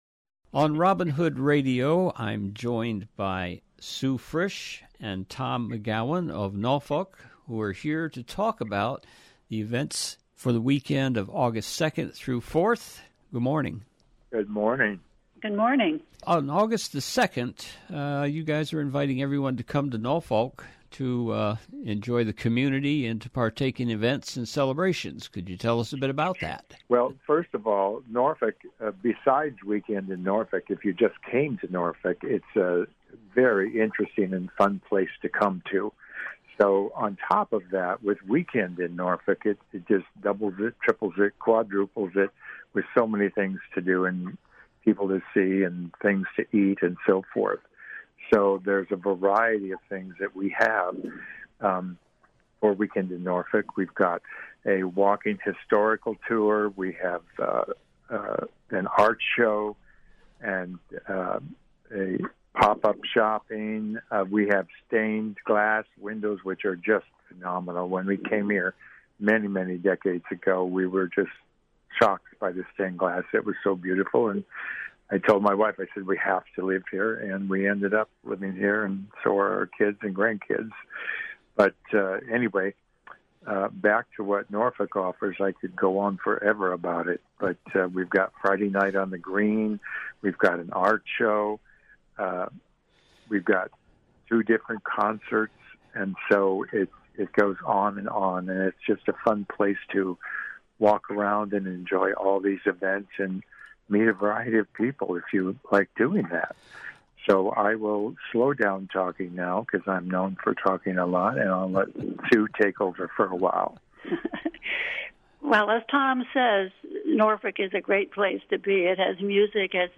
ROBIN HOOD RADIO INTERVIEWS Weekend in Norfolk - August 2 to 4, 2024 Jul 25 2024 | 00:16:24 1x 00:00 / 00:16:24 Subscribe Share Spotify RSS Feed Share Link Embed